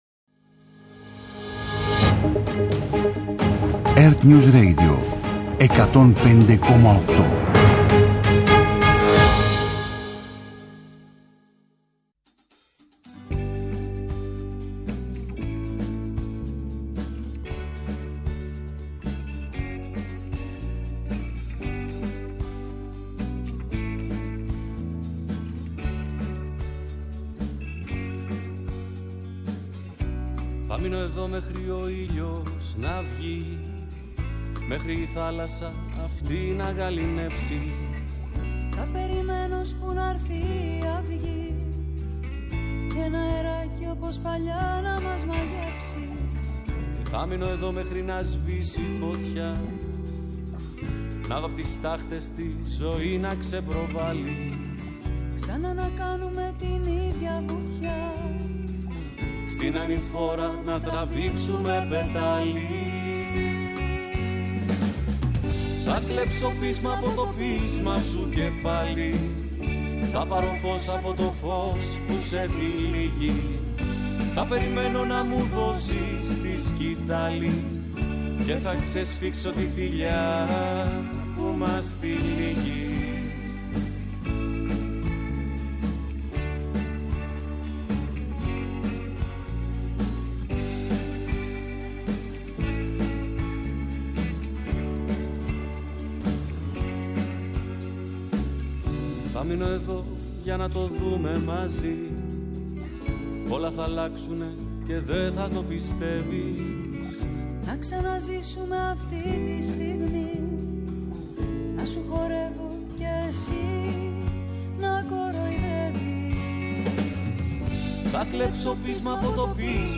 κριτικός κινηματογράφου